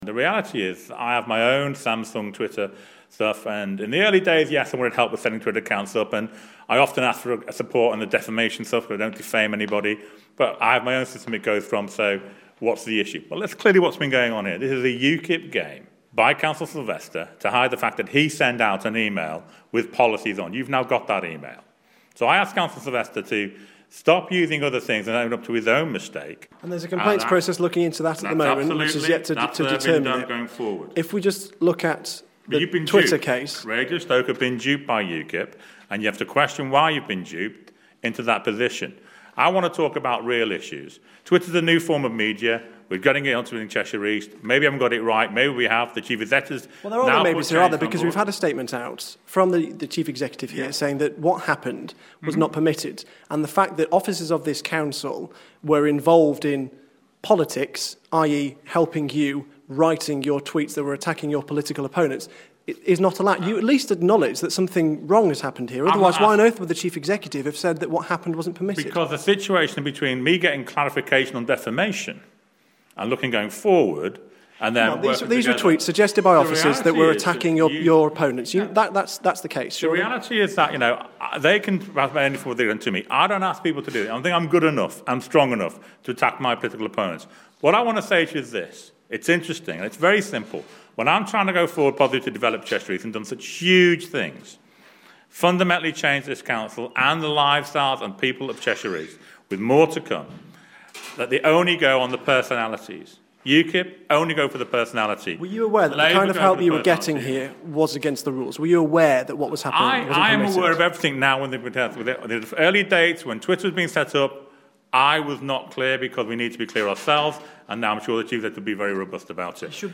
Tweets Cllr Jones interview